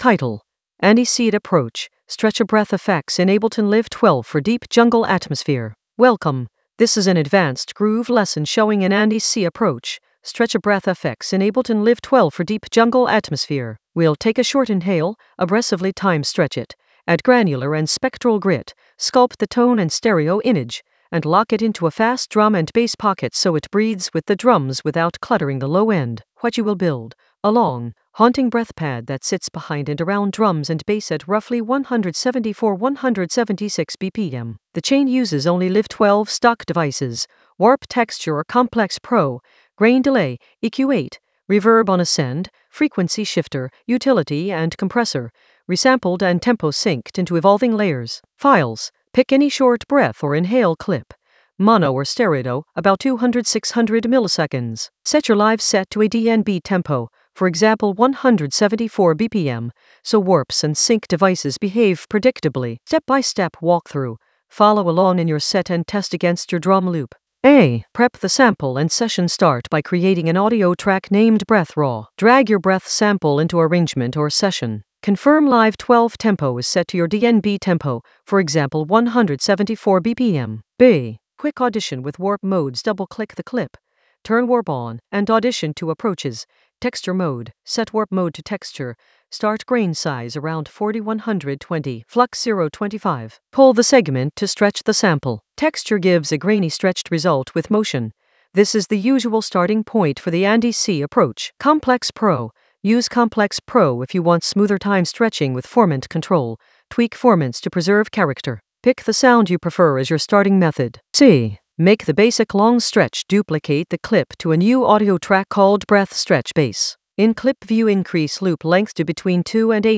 An AI-generated advanced Ableton lesson focused on Andy C approach: stretch a breath FX in Ableton Live 12 for deep jungle atmosphere in the Groove area of drum and bass production.
Narrated lesson audio
The voice track includes the tutorial plus extra teacher commentary.